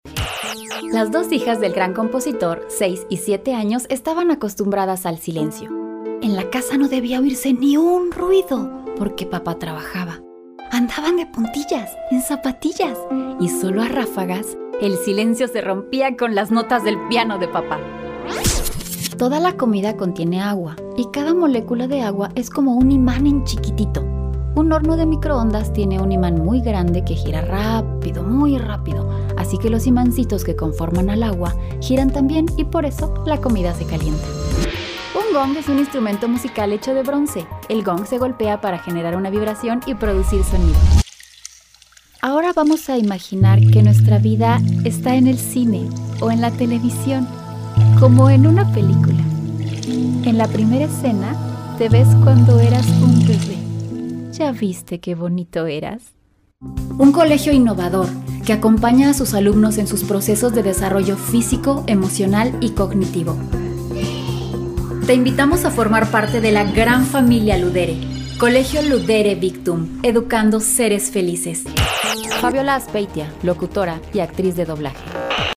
西班牙语女声
低沉|激情激昂|大气浑厚磁性|沉稳|娓娓道来|科技感|积极向上|时尚活力|神秘性感|调性走心|亲切甜美|素人